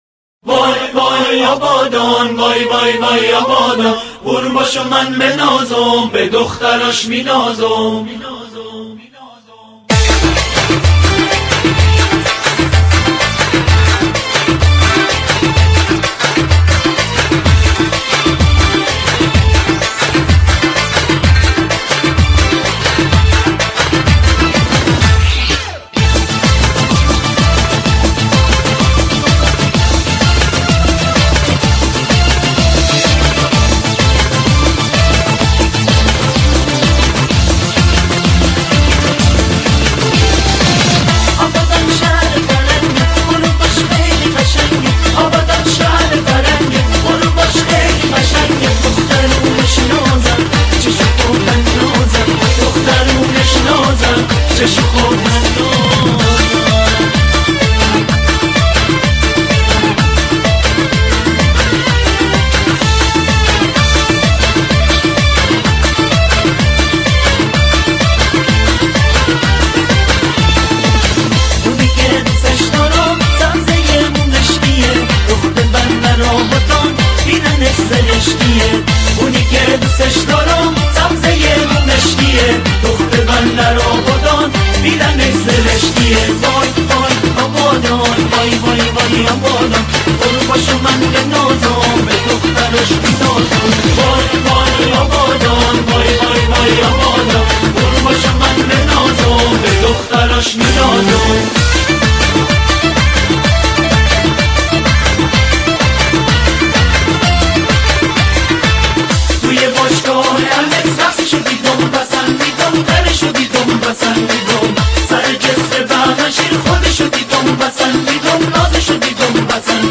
آهنگ بی کلام خیلی خیلی خیلی غمگین!😁😂